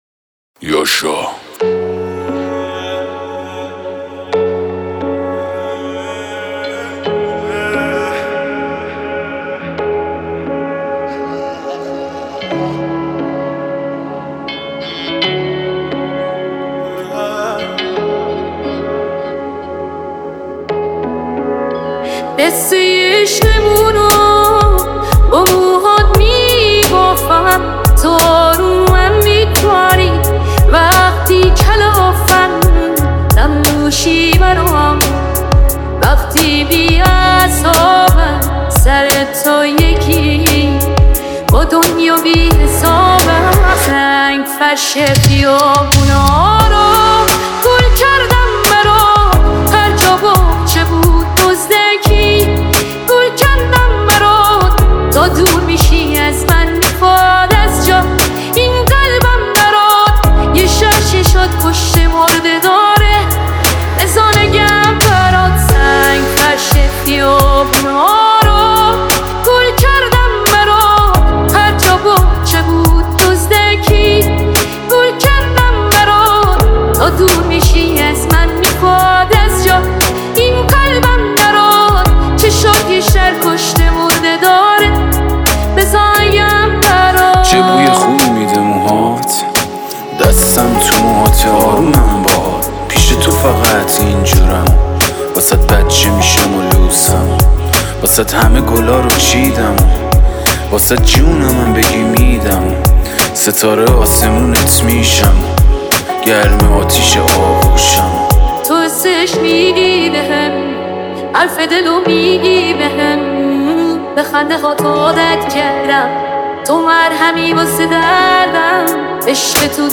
📯Remix Music Free Download📯